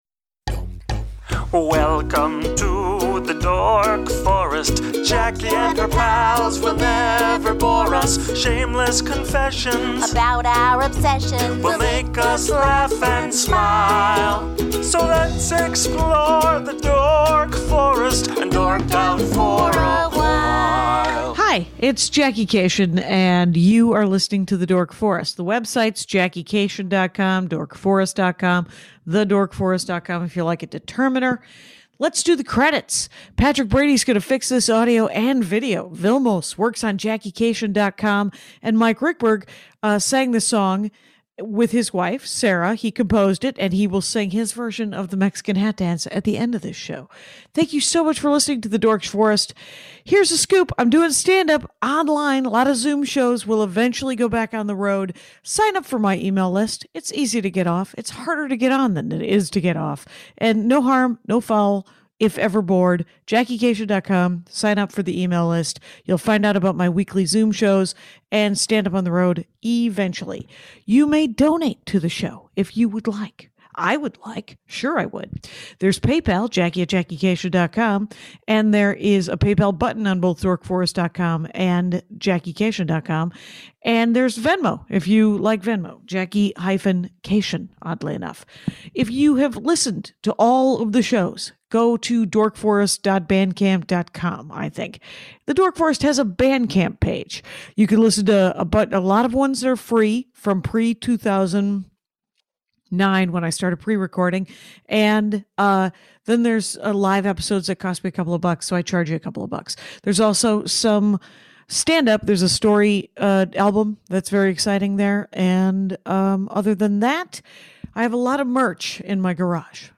and I talk over each other about Marvel.